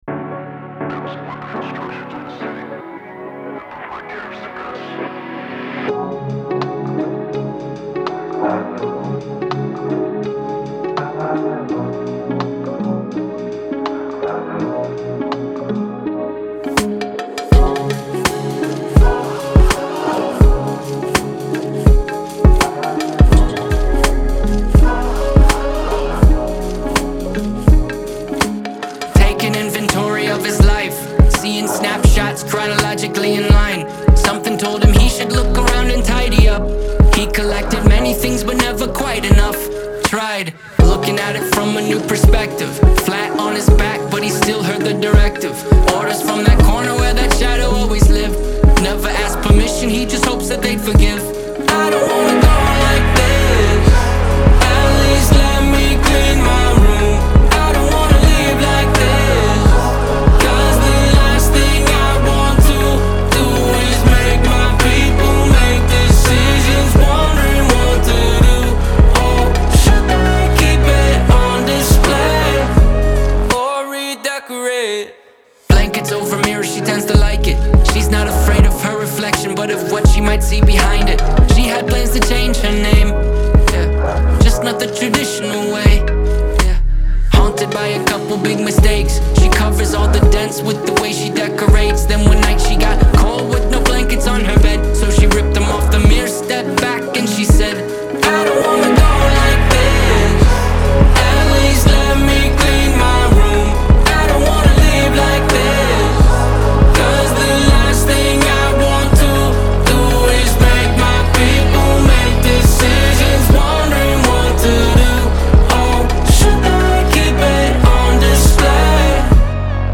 Genre : Indie Pop, Alternative